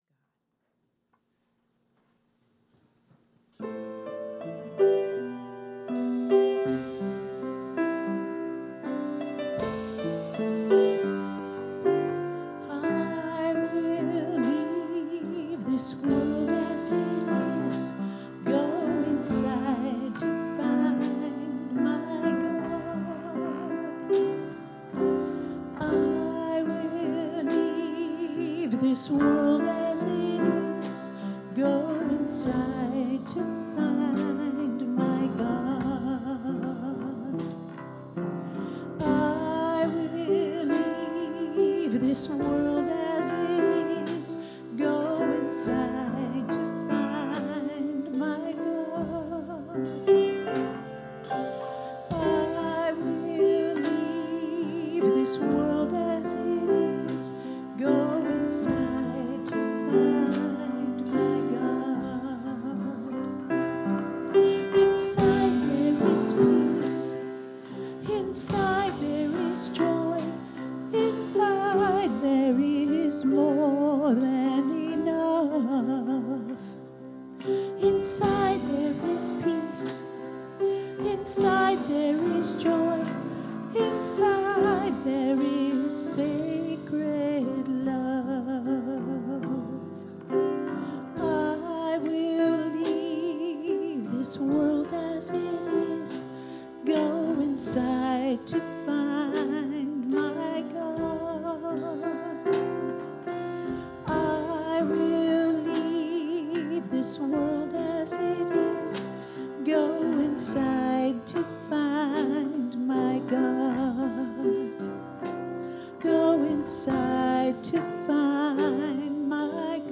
The audio recording (below the video clip) is an abbreviation of the service. It includes the Meditation, Message, and Featured Song.